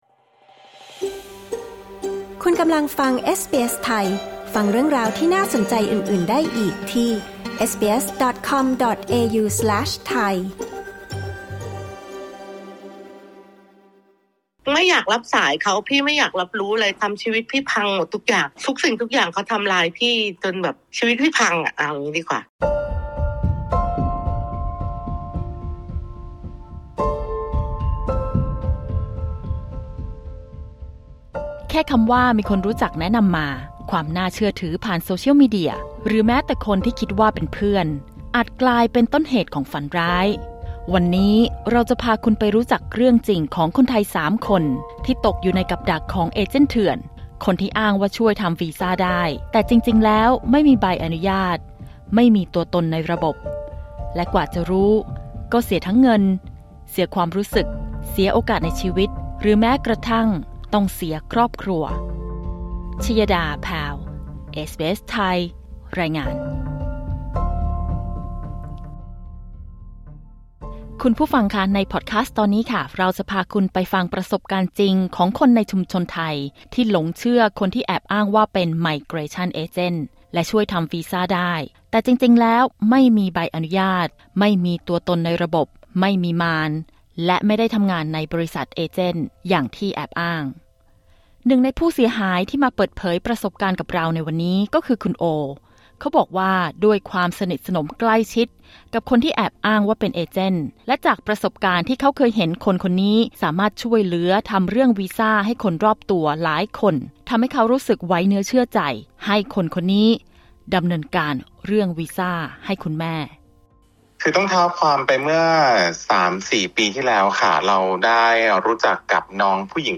ถูกหลอกทำวีซ่าในออสเตรเลีย เสียงจริงจากเหยื่อ ‘เอเจนท์เถื่อน’